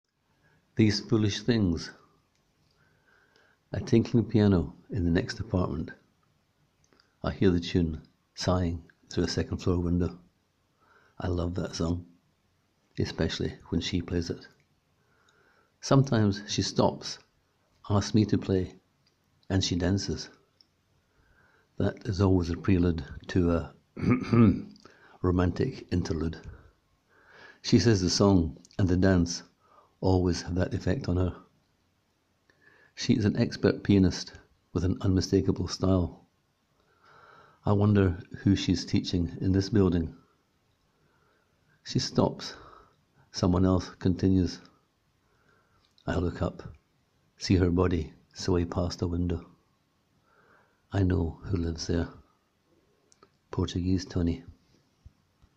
Click here to hear the writer read his words: